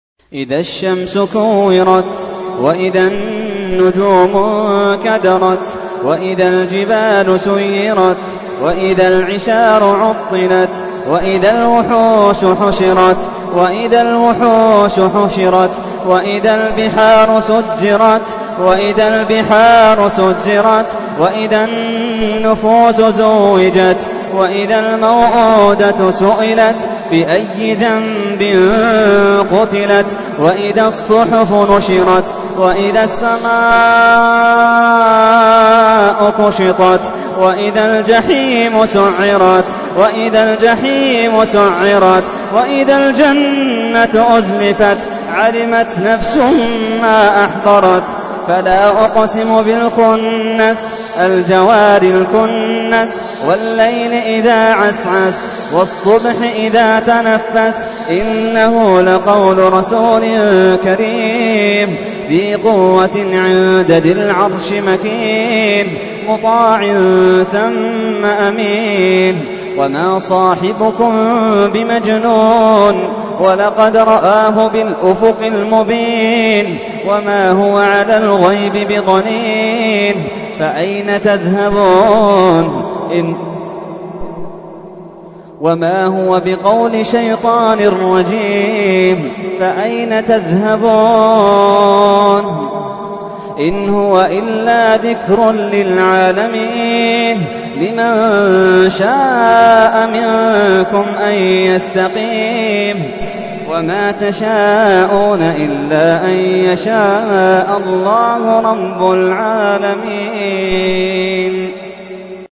تحميل : 81. سورة التكوير / القارئ محمد اللحيدان / القرآن الكريم / موقع يا حسين